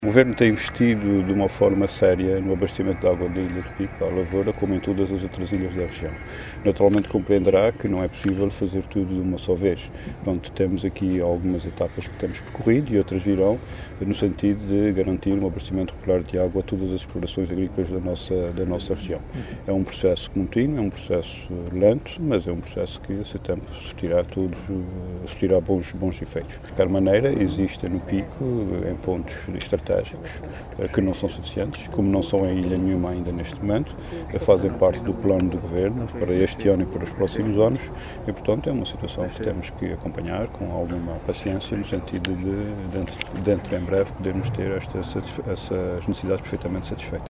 “O Governo tem investido de uma forma séria no abastecimento de água para a lavoura na ilha do Pico, tal como em todas as outras ilhas da Região” afirmou hoje Luís Neto Viveiros, em declarações aos jornalistas sobre as infraestruturas já executadas, no valor global de cerca de 850 mil euros.